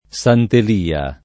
Sant el&a] top.